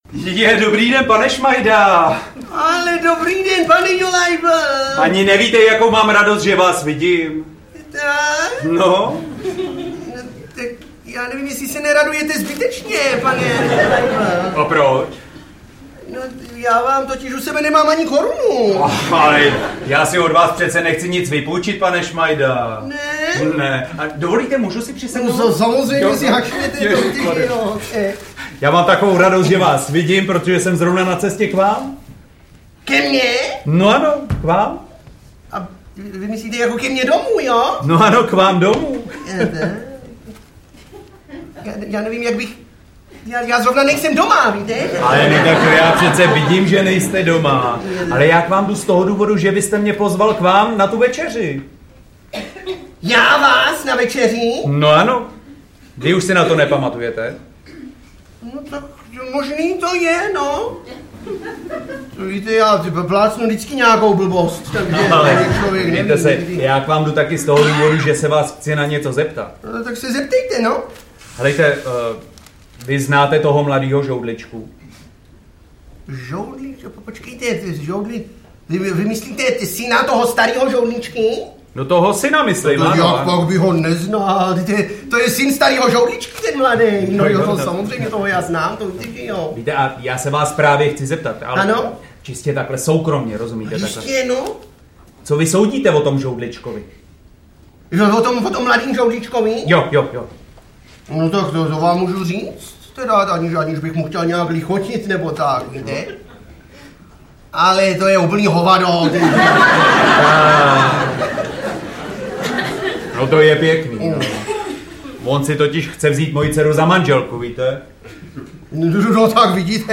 Zábavný večer plný humoru, legrace a vzpomínek na skvělého českého komika. Záznam úspěšného představení z divadla Semafor.